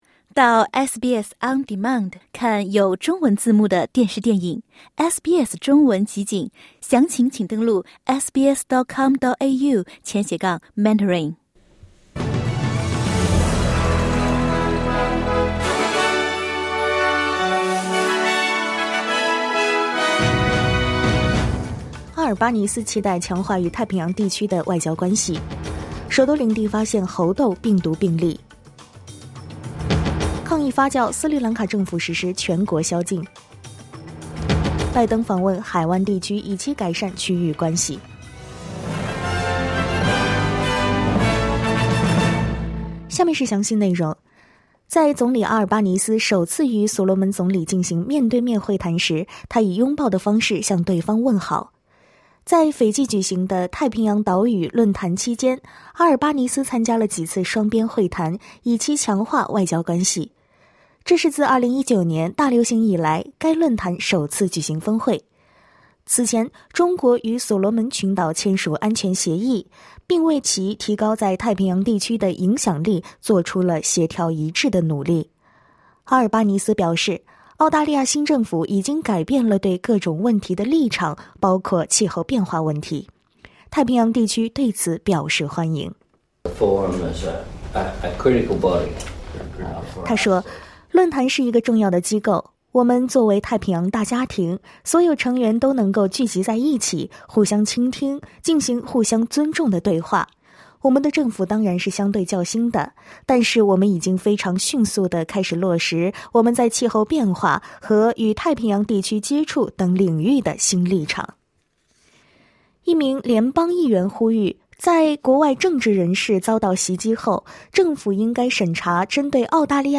SBS早新聞（7月14日）